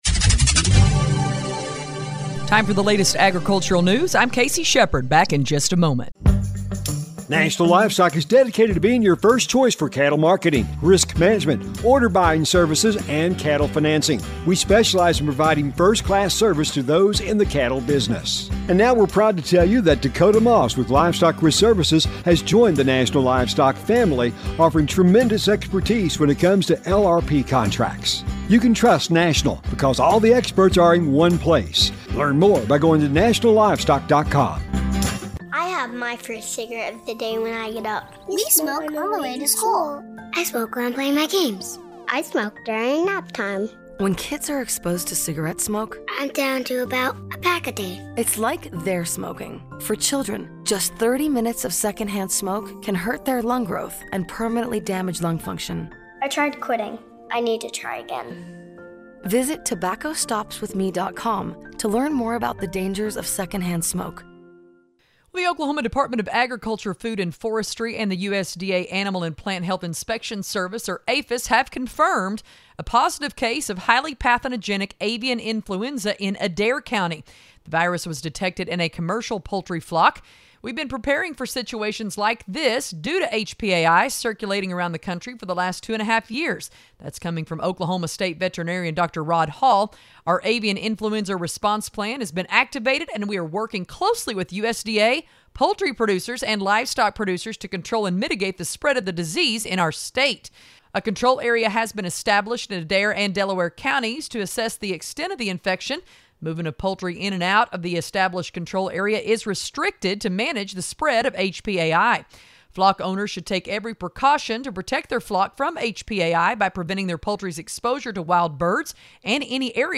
if you missed this morning's Farm News